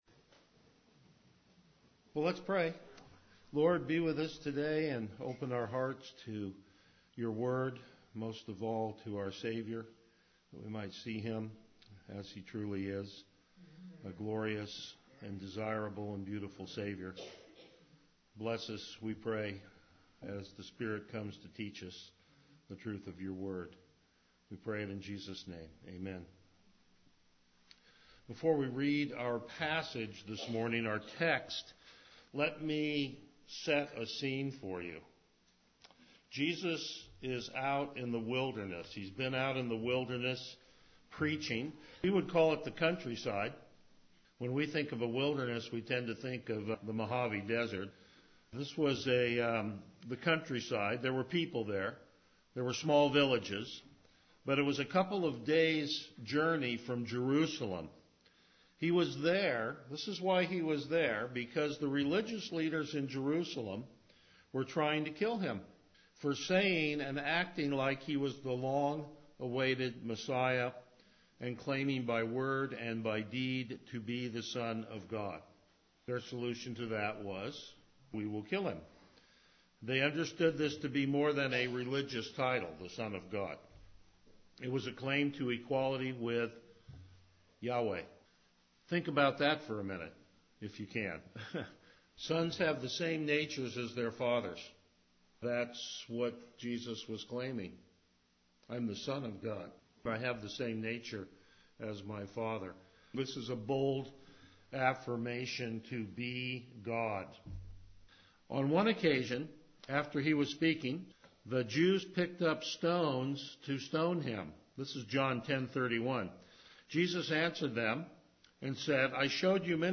Passage: John 11:1-27 Service Type: Morning Worship Topics: Topical Sermons